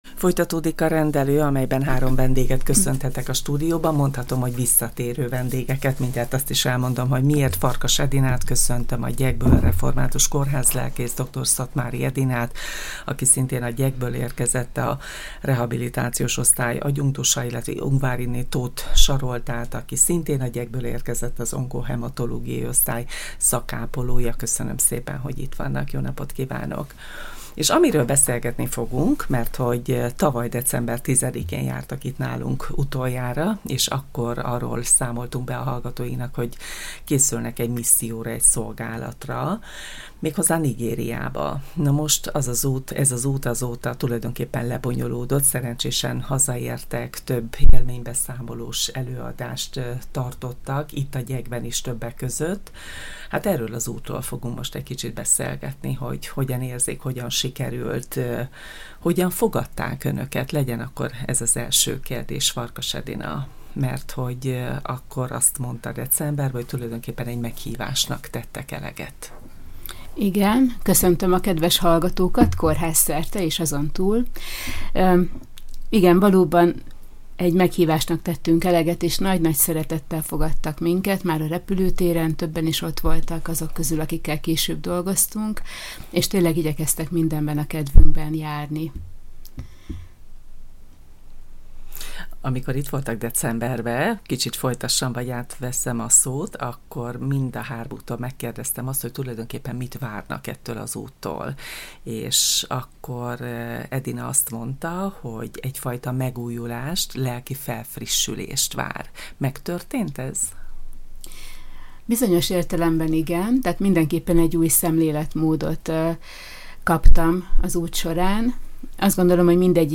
Január végén indultak útnak, most élménybeszámolót tartott annak a hatfős csoportnak a három tagja, akik egy meghívásnak eleget téve Nigériába utaztak missziós szolgálatra. Két egyházi kórházban és egy árvaházban segítettek beteg gyerekeknek és felnőtteknek.